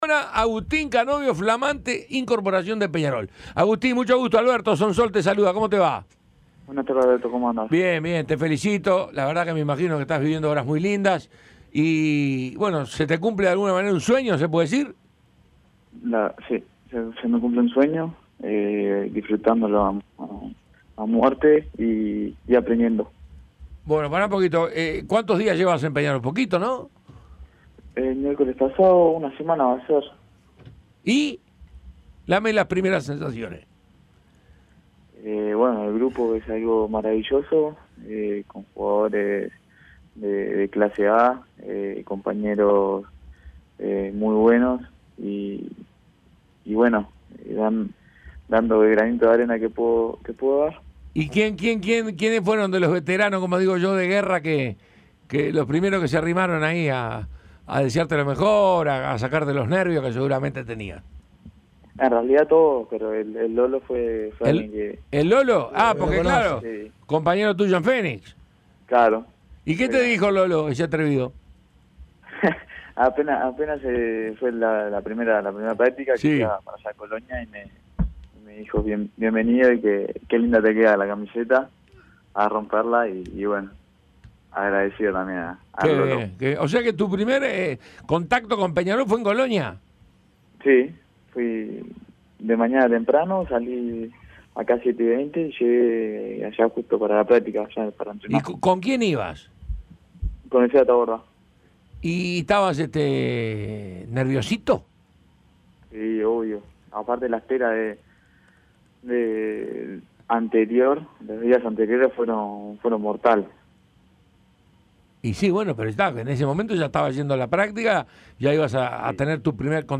El ex Fénix habló con el panel de Tuya y Mía respecto al desafío que implica jugar en un equipo grande y sus expectativas.